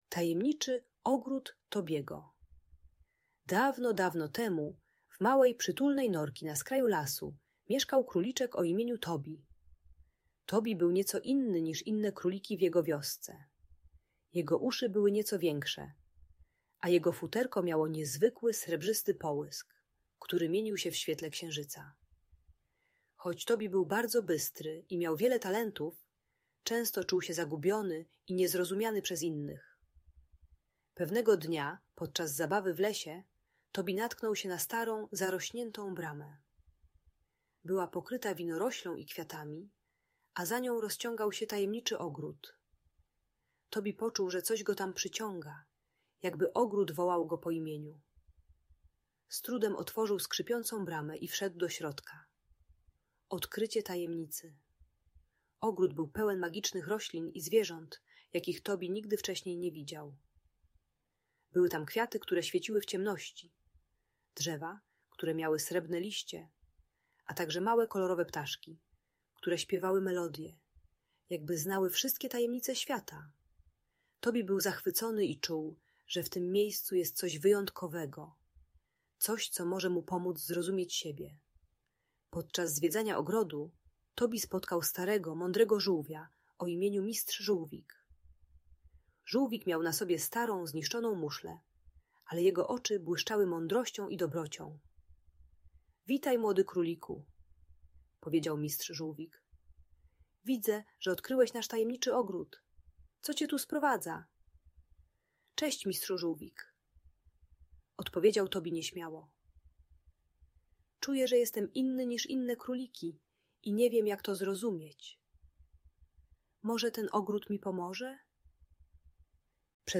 Historia Tajemniczego Ogrodu Tobiego - Audiobajka